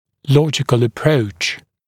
[‘lɔʤɪkl ə’prəuʧ][‘лоджикл э’проуч]логический подход